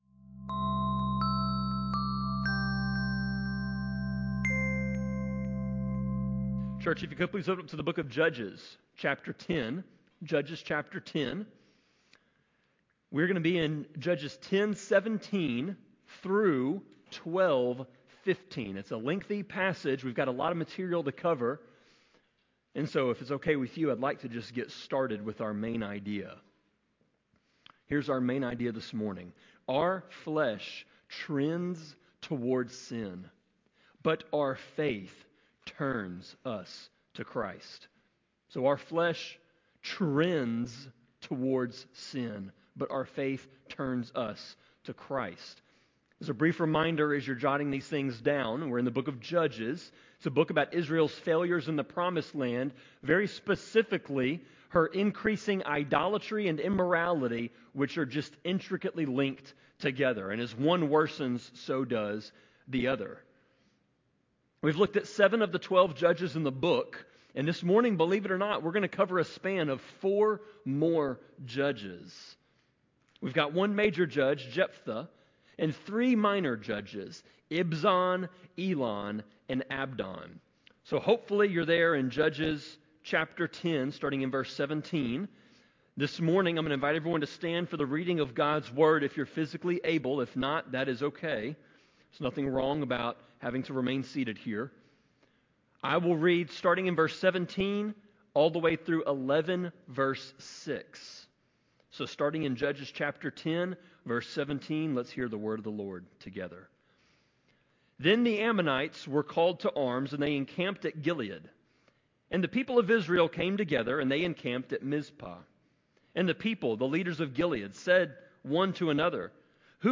Sermon-26.1.18-CD.mp3